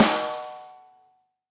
Snare 57 reverb 8.wav